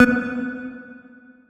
key-press-2.wav